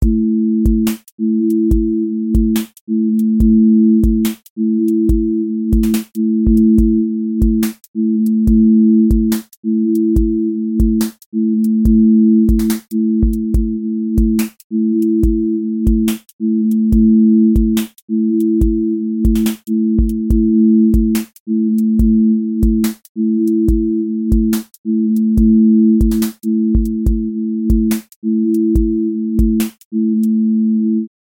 QA Listening Test drill Template: drill_glide
drill glide tension with sliding low end